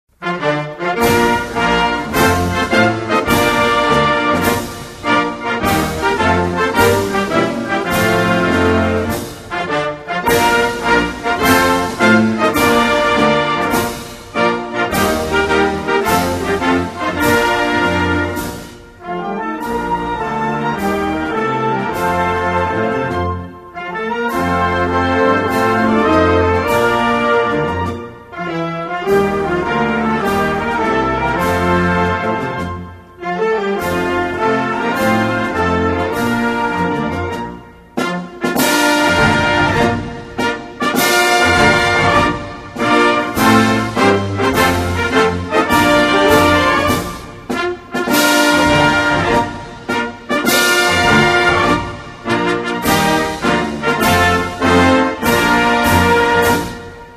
Cameroon-Anthem.mp3